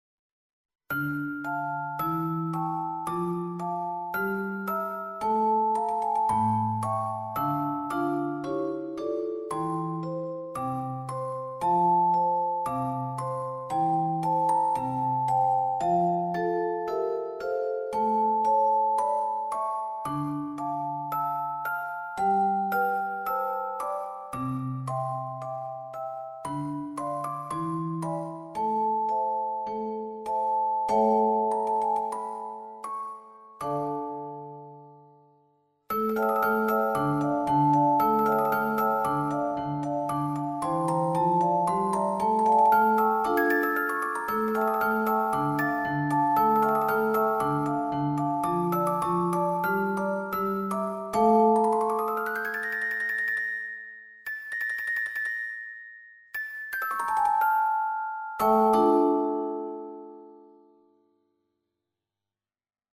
オルゴール特集Music box